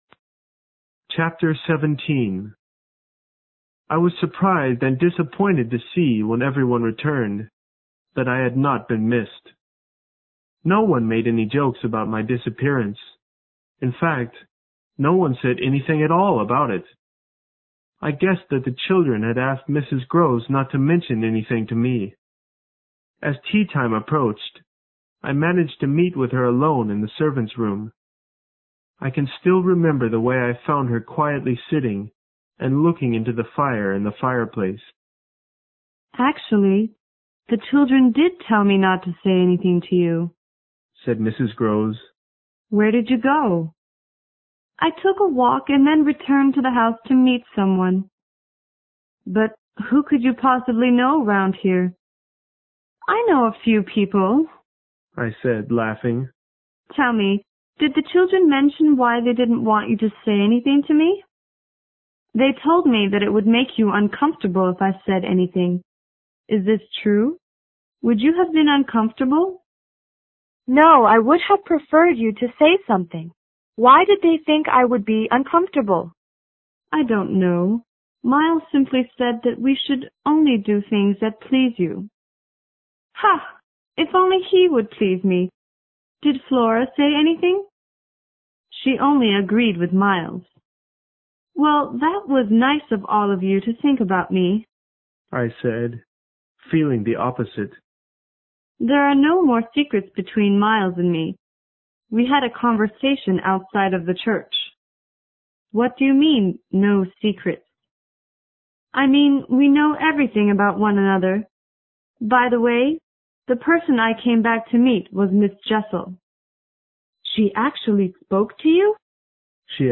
有声名著之螺丝在拧紧chapter17 听力文件下载—在线英语听力室